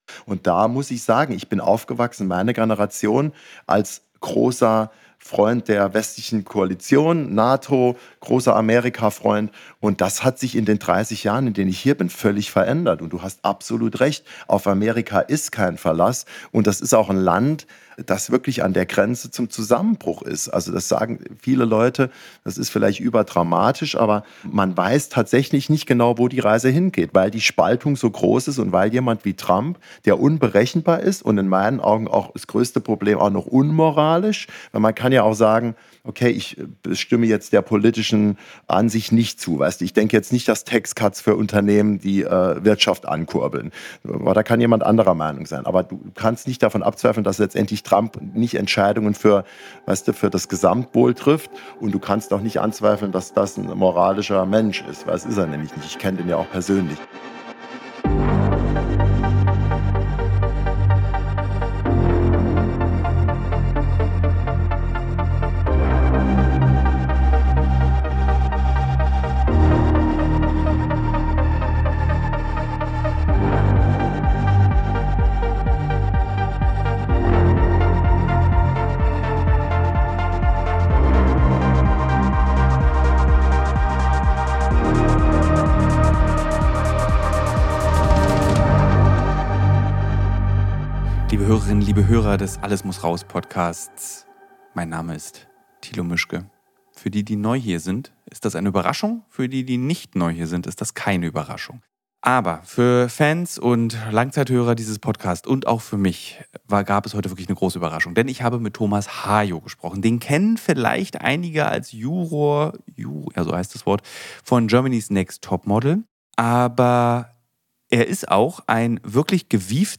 In dieser Sonderfolge zur US-Wahl trifft Thilo Mischke auf Thomas Hayo, der aus dem Herzen New Yorks berichtet – von Heidis Halloweenparty über die Stimmung der Amerikaner bis hin zu den absurden Riten und der politischen Polarisierung. Die beiden werfen einen Blick auf die bevorstehenden Wahlen, diskutieren die Chancen und Risiken eines möglichen Comebacks von Trump und analysieren, wie sehr die Showbusiness-Kultur die politische Landschaft in den USA prägt.